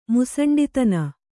♪ musaṇḍitana